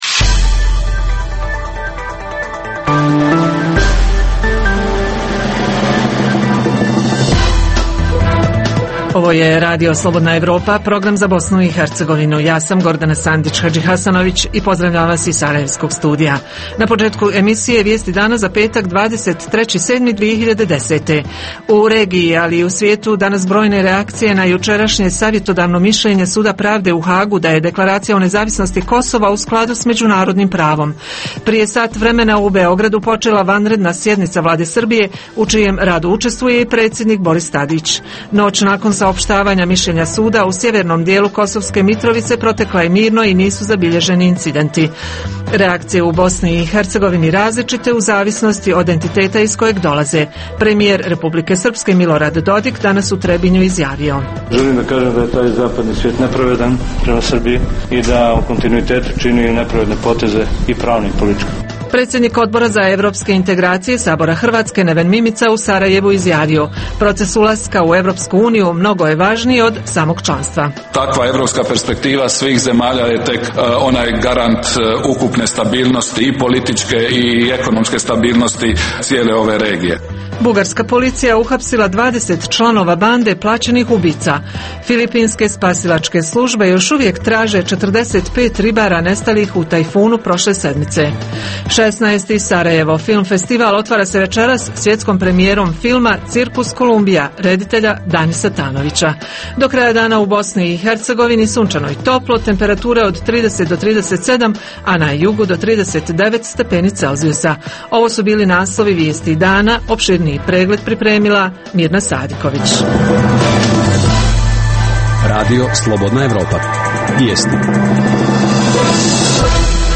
O filmu koji otvara SFF „Cirkus Kolumbia“, govori reditelj Danis Tanović.